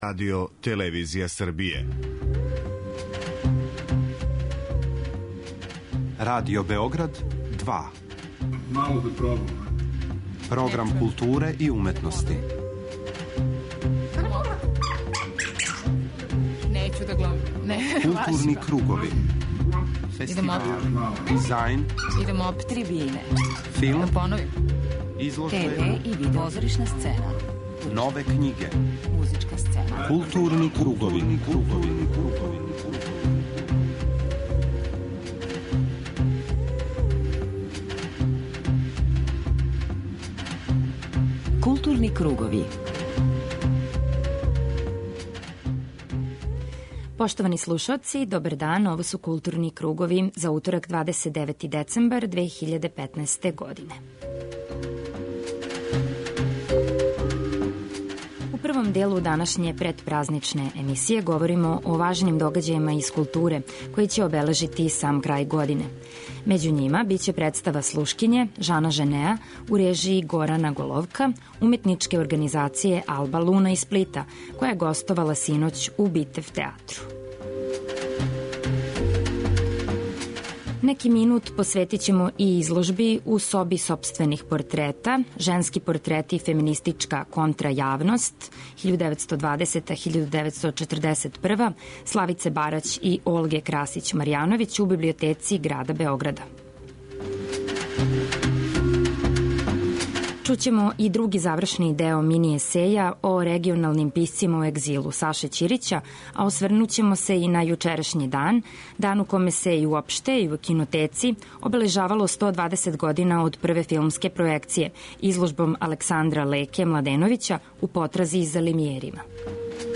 преузми : 41.19 MB Културни кругови Autor: Група аутора Централна културно-уметничка емисија Радио Београда 2.